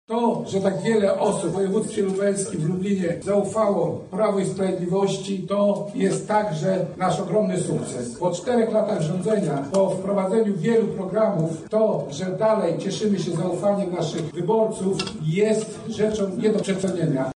O tym co przyczyniło się do ponownego sukcesu wyborczego partii rządzącej mówi Krzysztof Michałkiewicz, wiceminister Rodziny, Pracy i Polityki Społecznej oraz prezes zarządu okręgowego Prawa i Sprawiedliwości